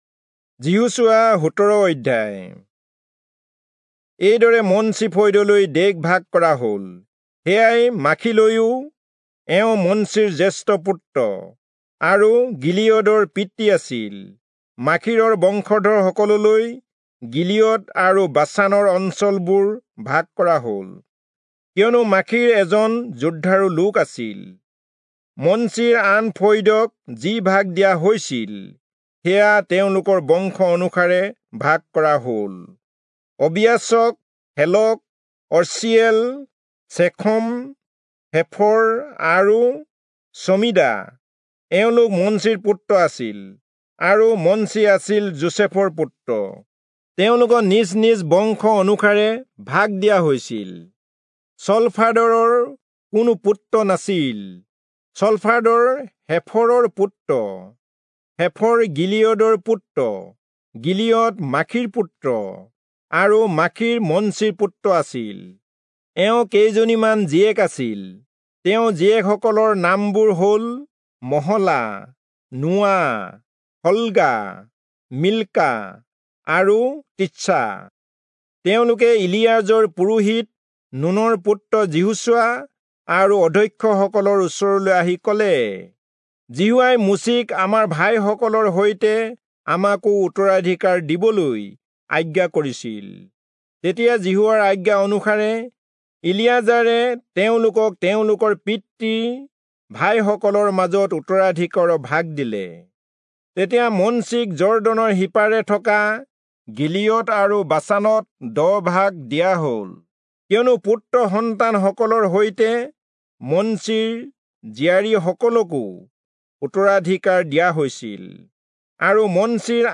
Assamese Audio Bible - Joshua 9 in Net bible version